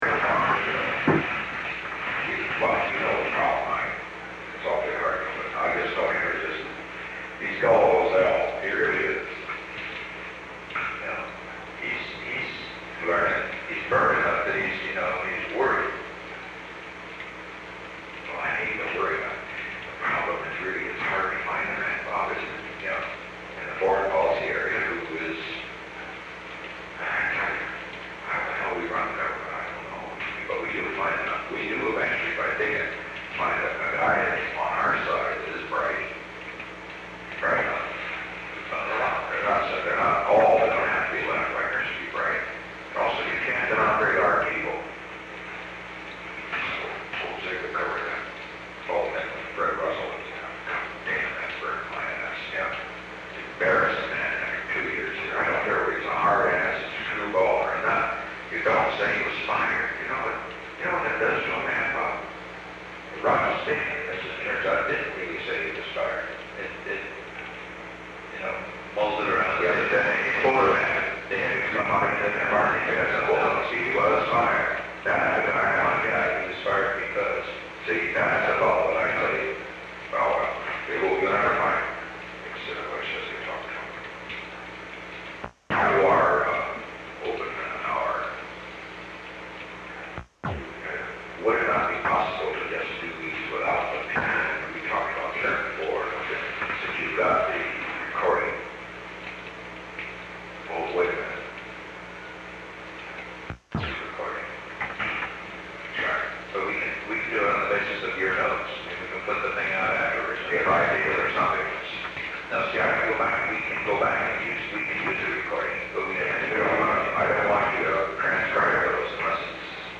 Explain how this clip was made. These tapes comprise 4 conversations recorded between February 16 and February 23, 1972 in the Oval Office (OVAL).